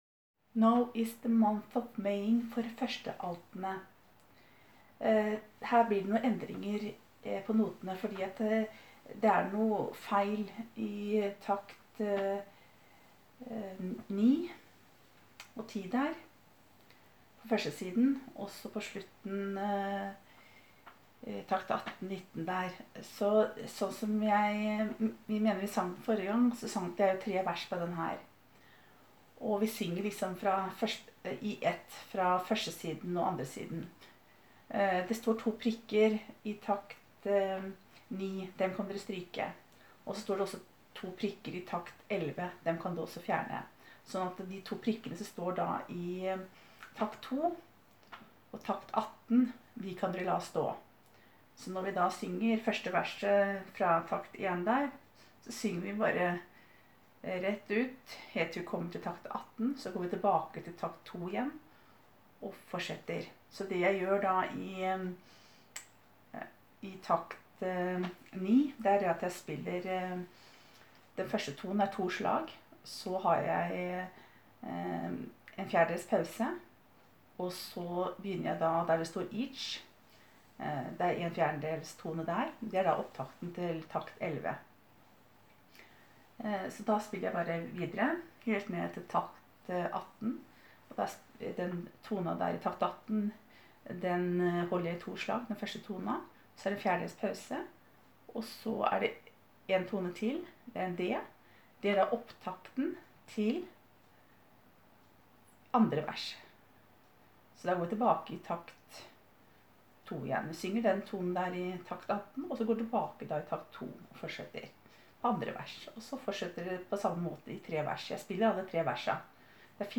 Jubileum 2019 Alter
1.Alt: